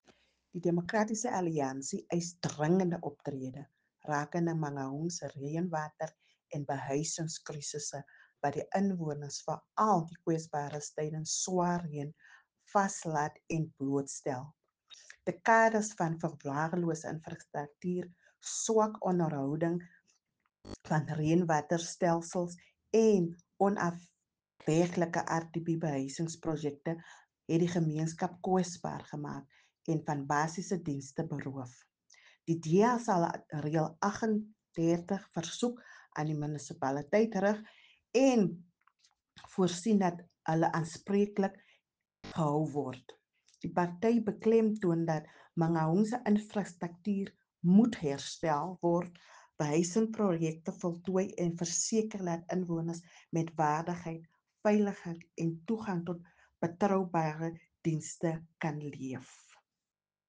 Afrikaans soundbites by Cllr Raynie Klaasen and